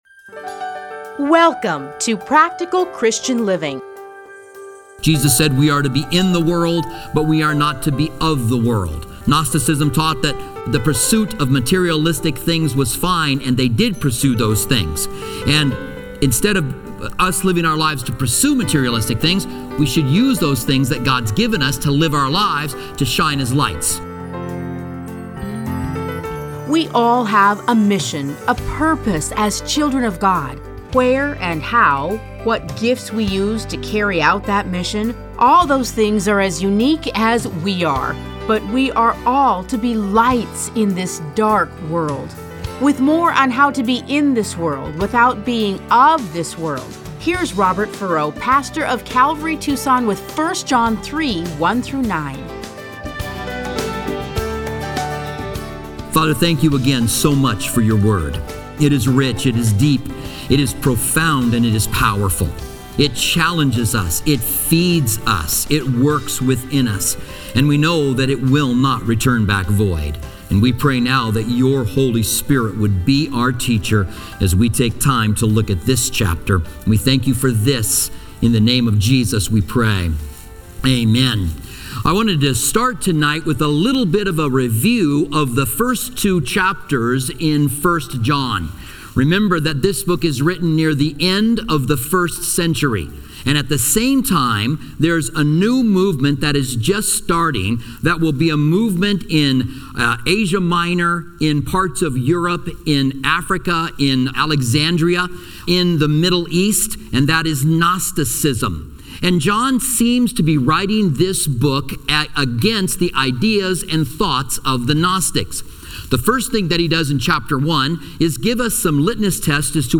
Listen to a teaching from 1 John 3:1-9.